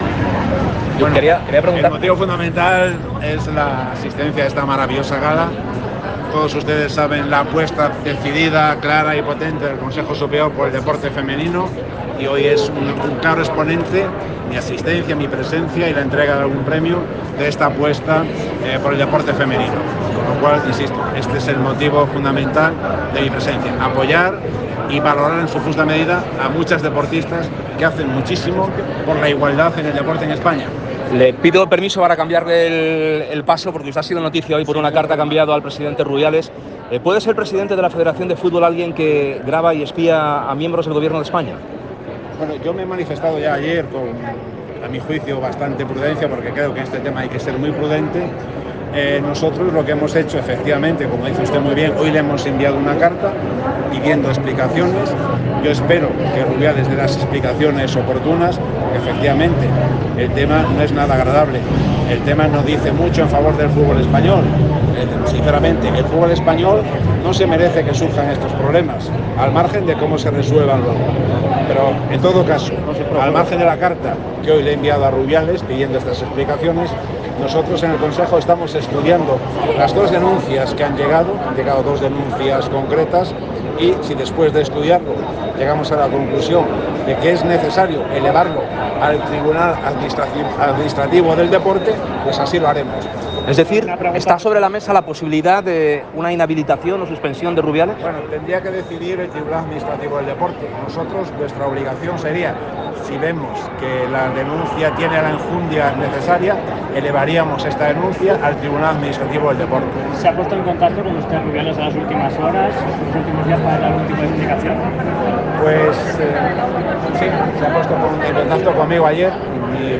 AUDIO: ENTREVISTA COMPLETA A JOSÉ MANUEL FRANCO